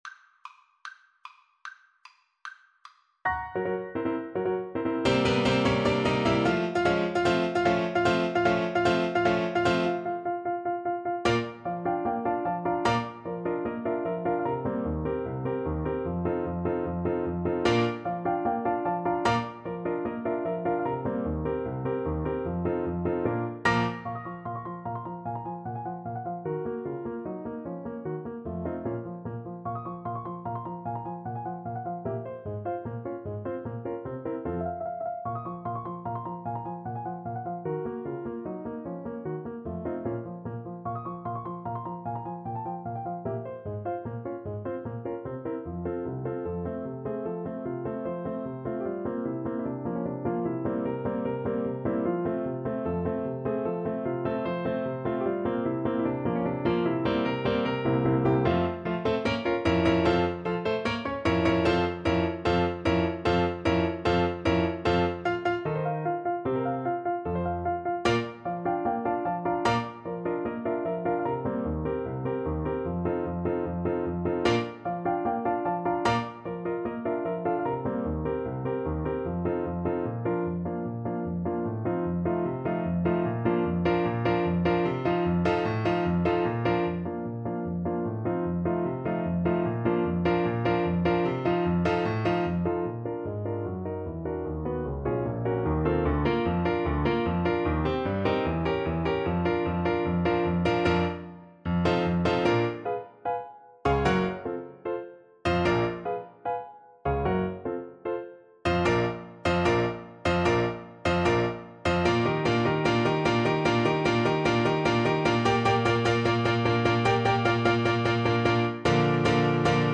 Play (or use space bar on your keyboard) Pause Music Playalong - Piano Accompaniment Playalong Band Accompaniment not yet available transpose reset tempo print settings full screen
2/4 (View more 2/4 Music)
Bb major (Sounding Pitch) C major (Clarinet in Bb) (View more Bb major Music for Clarinet )
Allegro vivacissimo ~ = 150 (View more music marked Allegro)
Classical (View more Classical Clarinet Music)